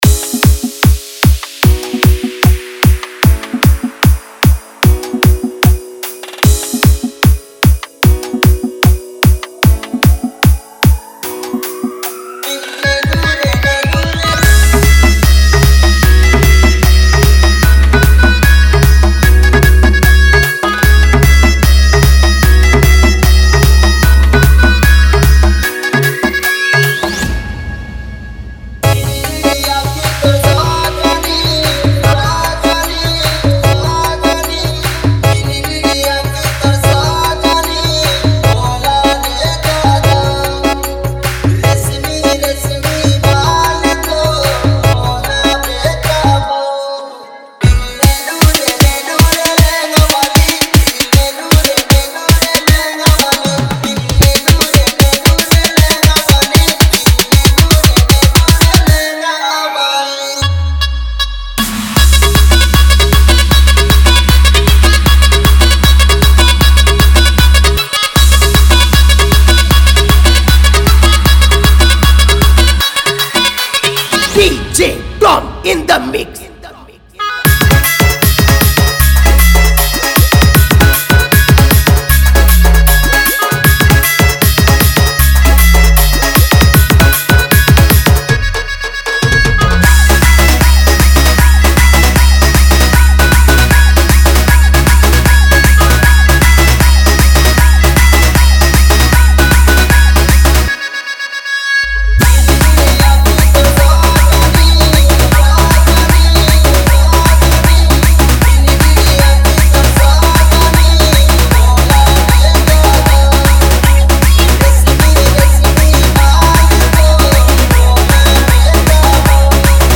edm mashup dj music